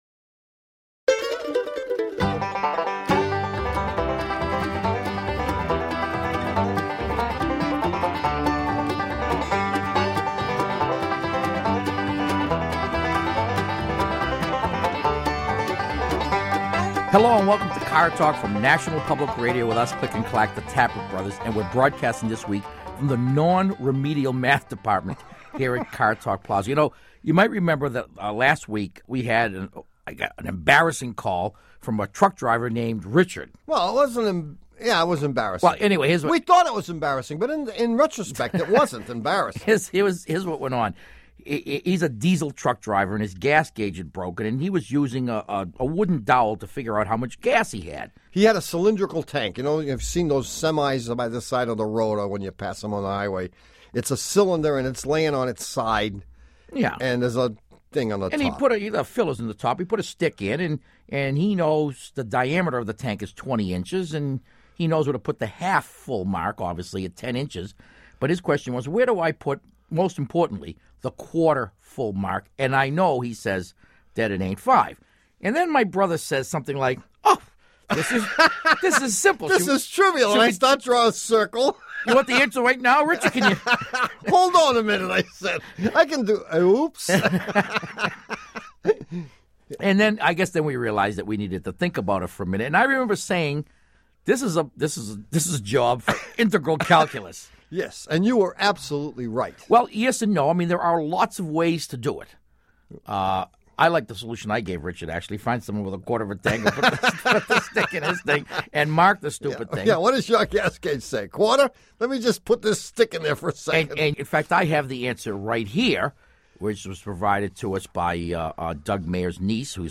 Car Talk caller